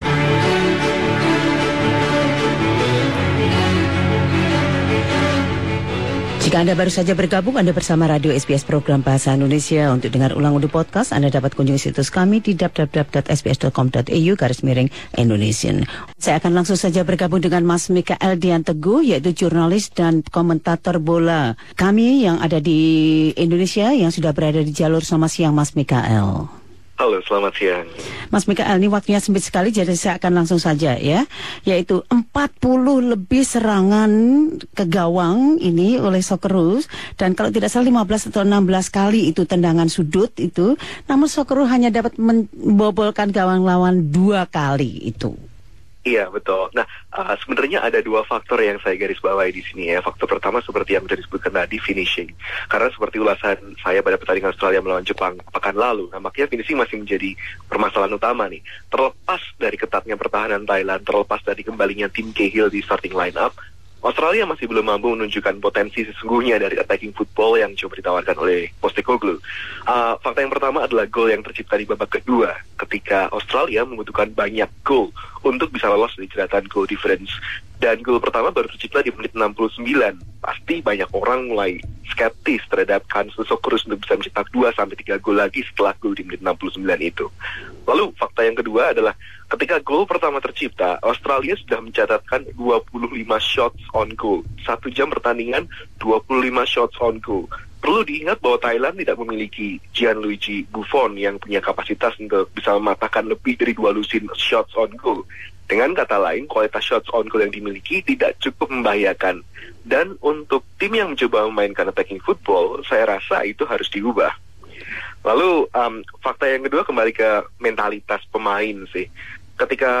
Jurnalis dan komentator bola